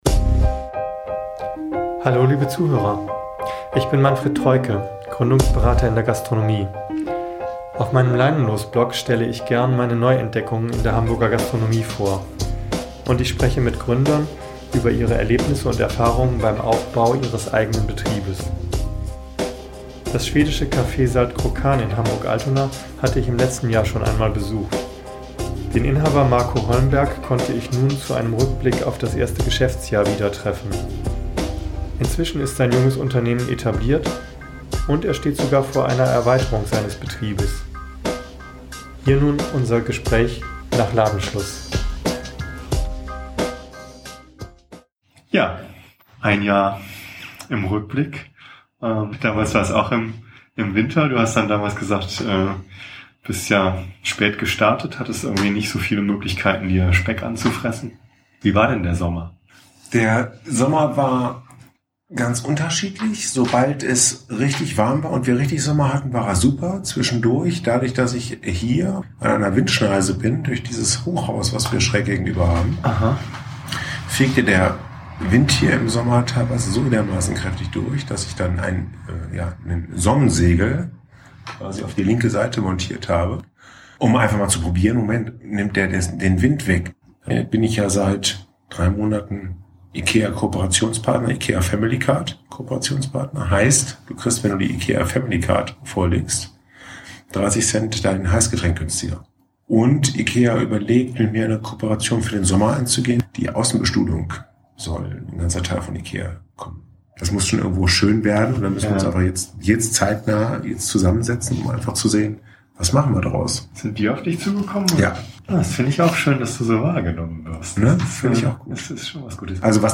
Gründergeschichten, Interview